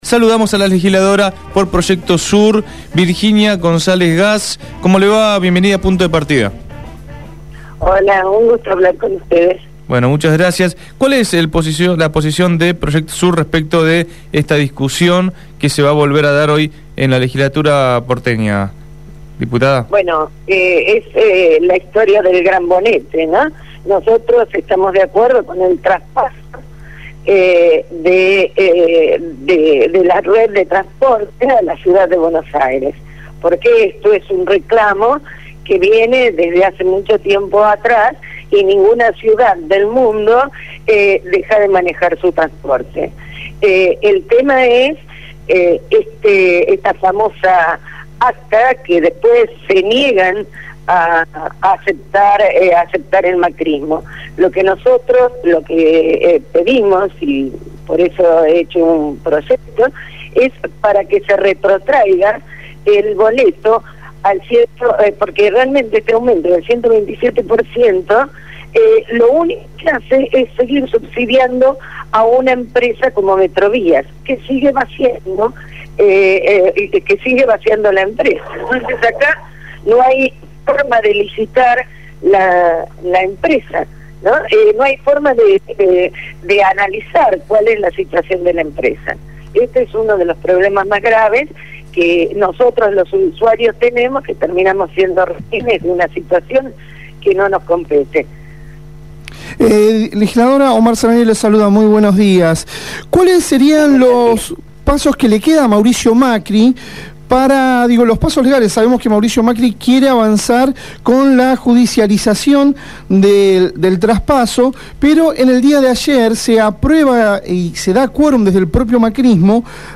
Virginia Gonzalez Gass, legisladora porteña por Proyecto Sur, habló en Punto de Partida.